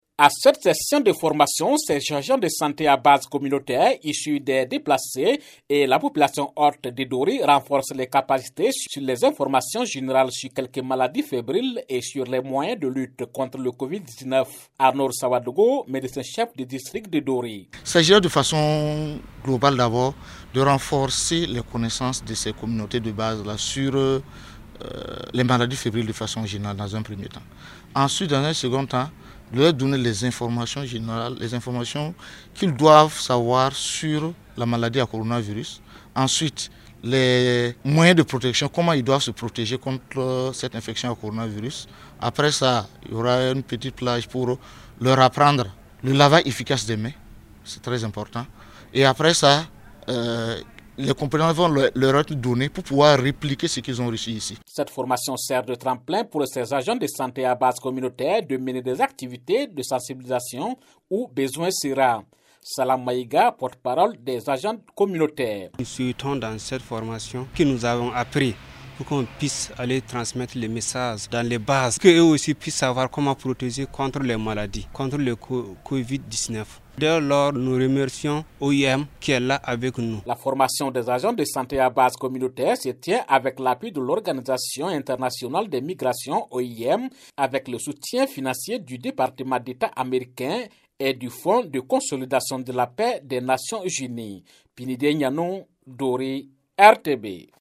De Dori, le reportage